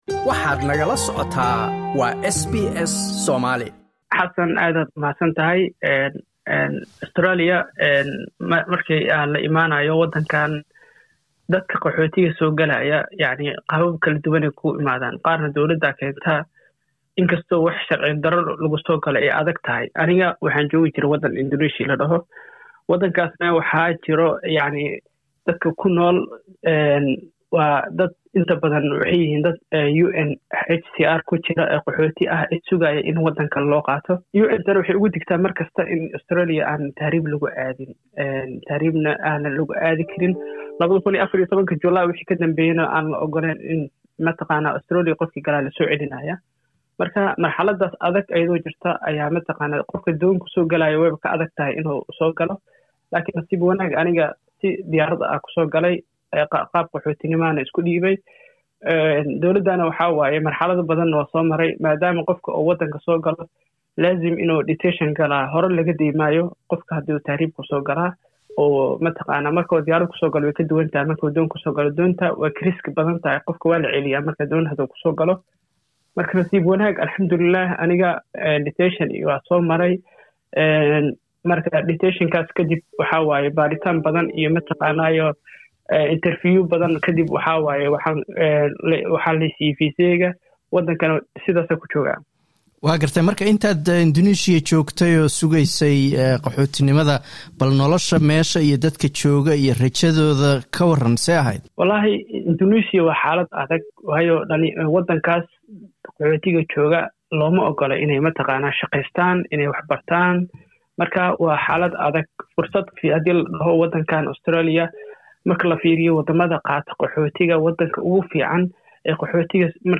waxaan waraysi ku saabsan qaxootinimada